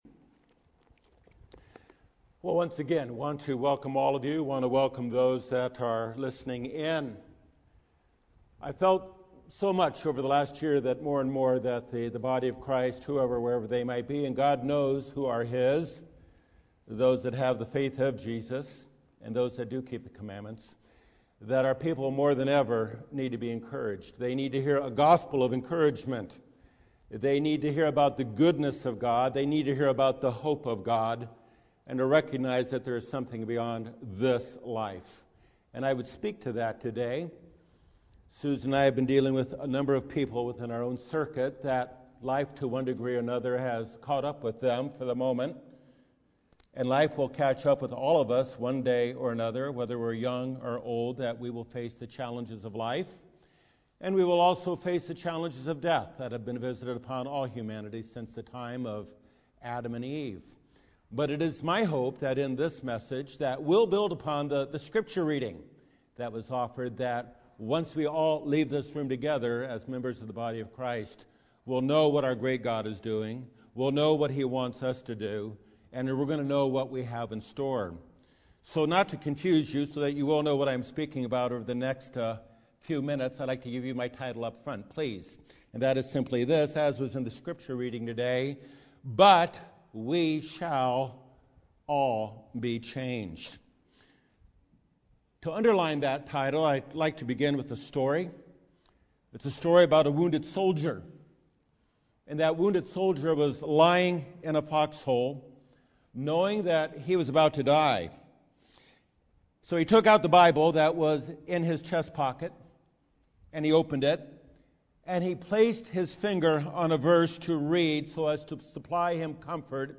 This message of encouragement concerning the 7th Trumpet brings the Body of Christ into awareness of the incredible gift of God to raise His faithful servants from death to newness of life before Him and one another---forever! We are told to "comfort one another" with this reality.